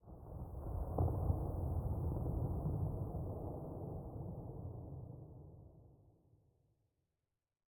basaltground3.ogg